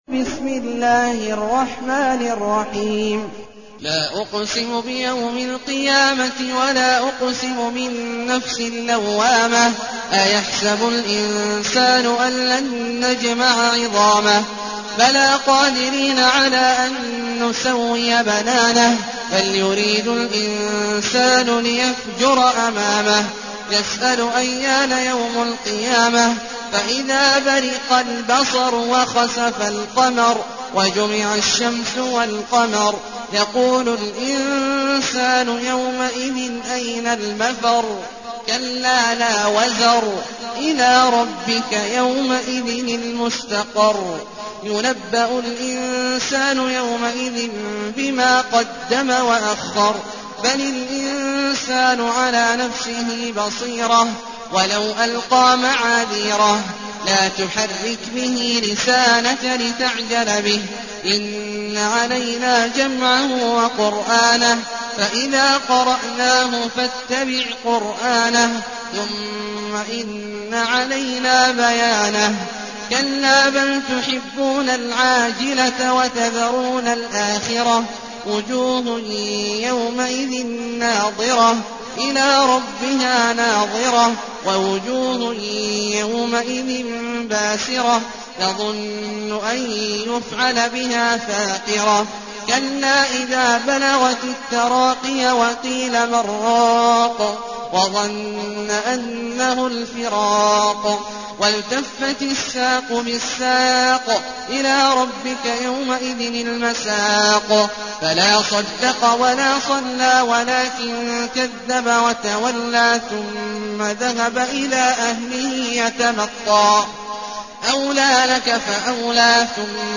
المكان: المسجد الحرام الشيخ: عبد الله عواد الجهني عبد الله عواد الجهني القيامة